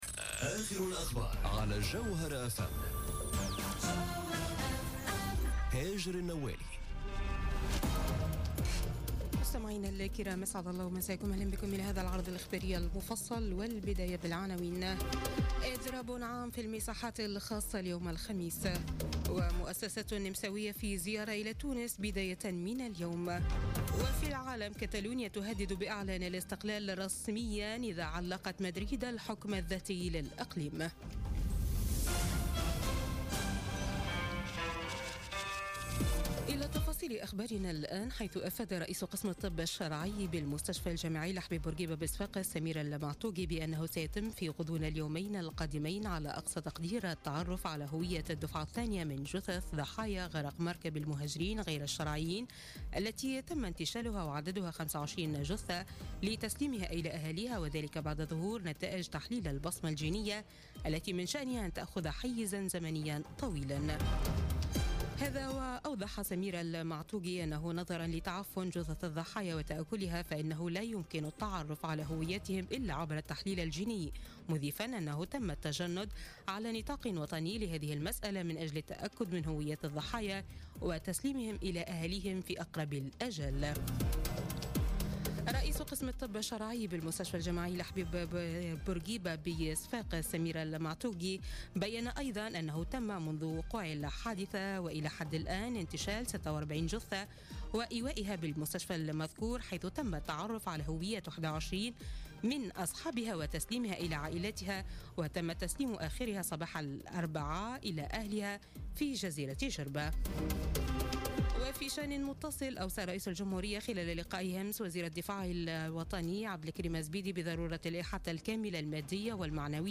نشرة أخبار منتصف الليل ليوم الخميس 19 أكتوبر 2017